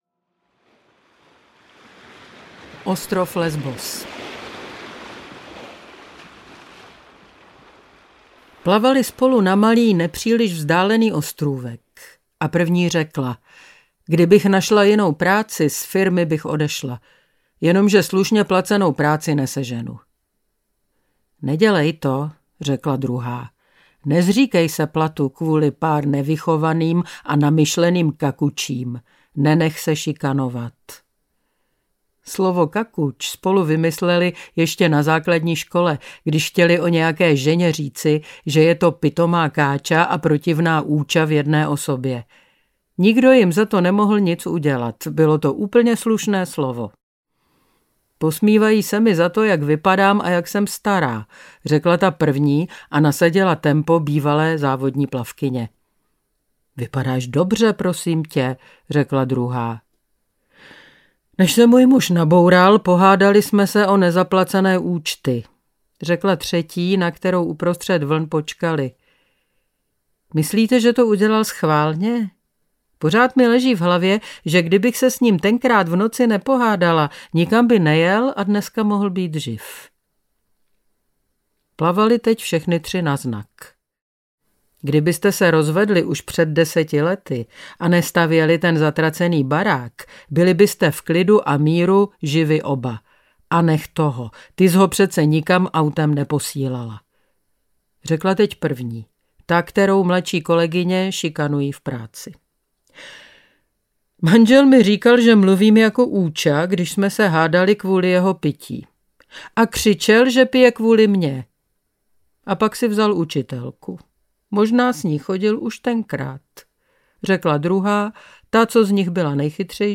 Nějaké ženy a jacísi muži audiokniha
Ukázka z knihy
Nahráno v studiu Cireal